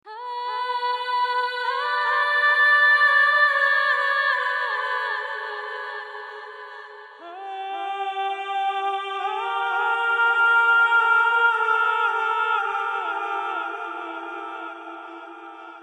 描述：女性男性声带镜像
Tag: 120 bpm Ambient Loops Vocal Loops 2.66 MB wav Key : Unknown